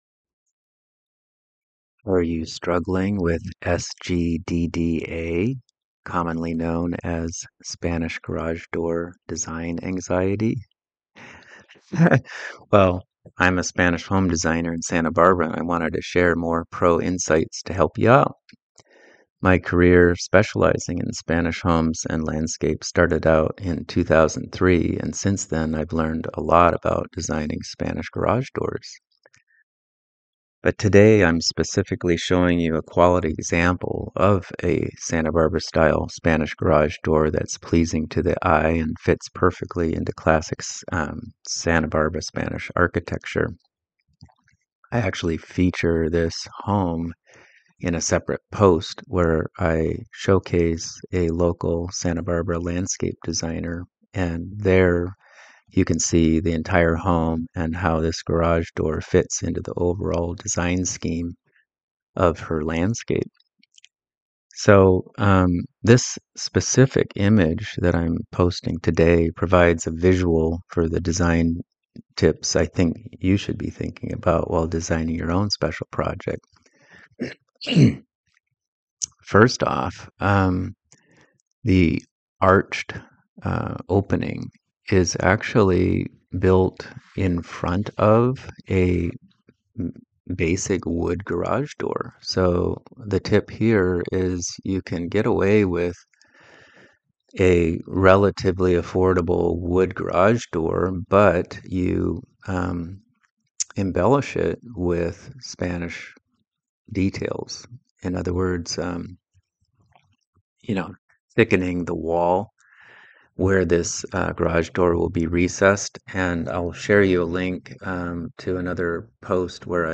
Listen as I walk you through pro designer tips on how you can be thinking of your Spanish garage door.